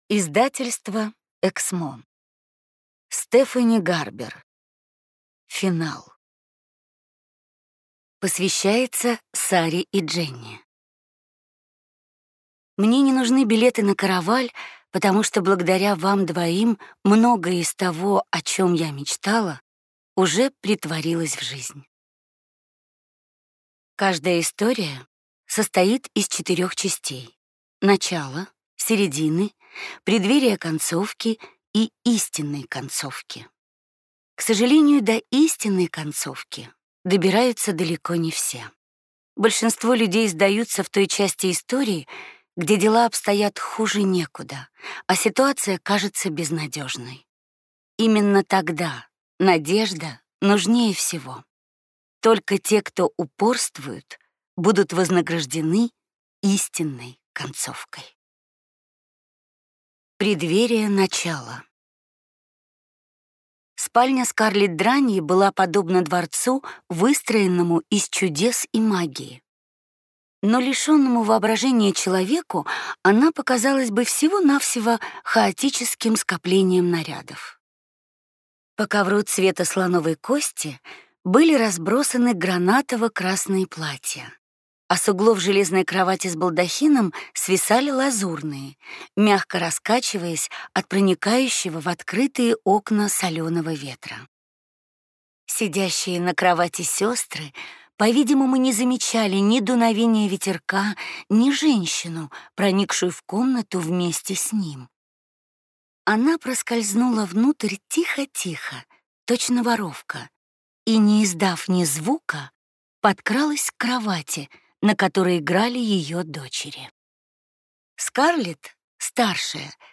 Аудиокнига Финал | Библиотека аудиокниг
Прослушать и бесплатно скачать фрагмент аудиокниги